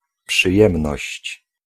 Ääntäminen
France (Paris): IPA: [ɛ̃ ple.ziʁ]